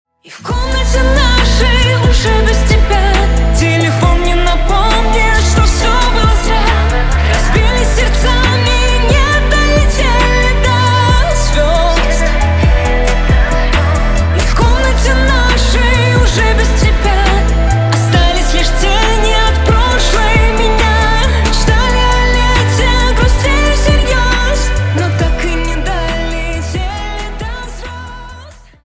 • Качество: 320, Stereo
грустные
красивый женский голос